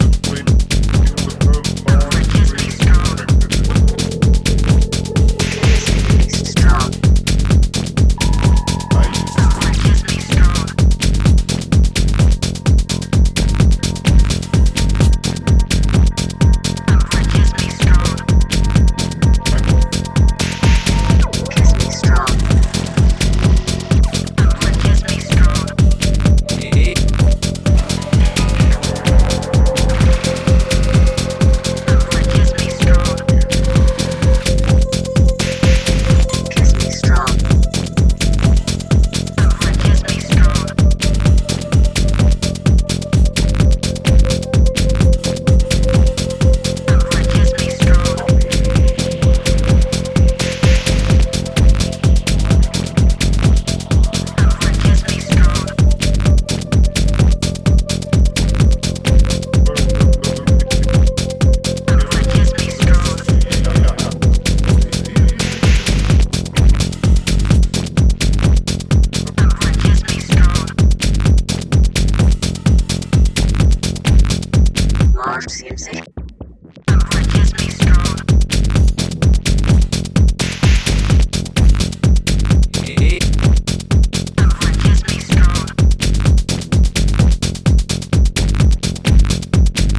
いかついボトムとフリーキーなエフェクトが特徴の